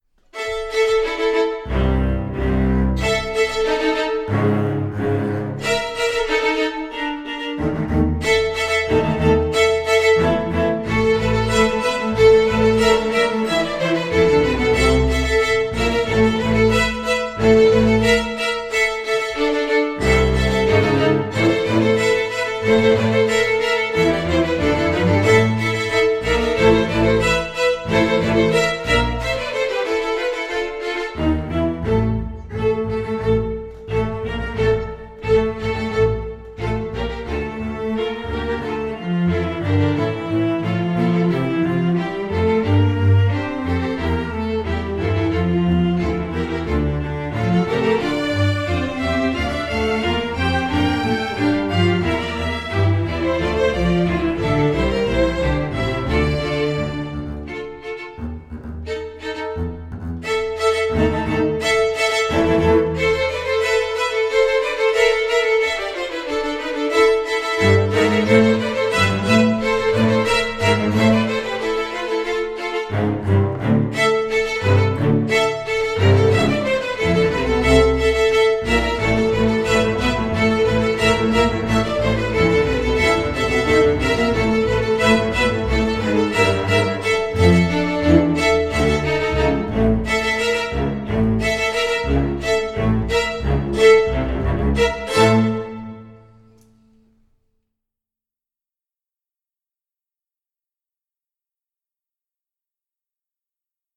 the violins battle the low strings in an epic clash.